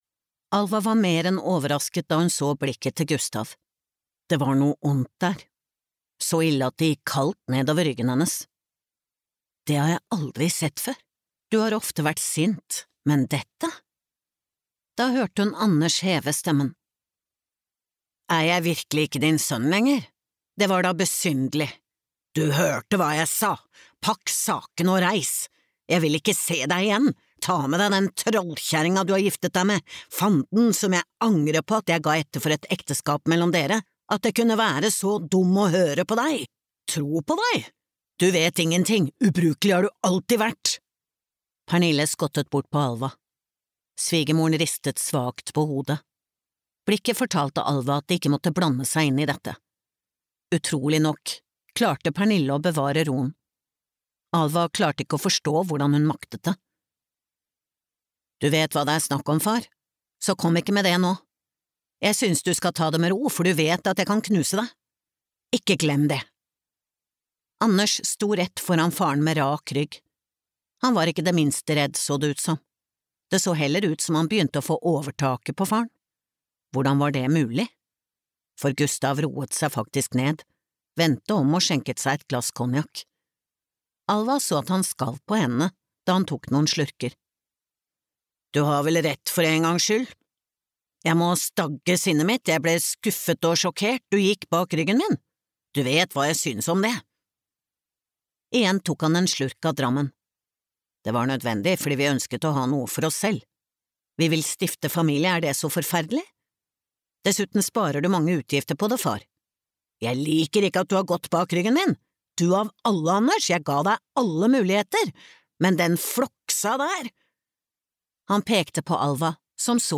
Skjebnesvangre valg (lydbok) av Jorunn Johansen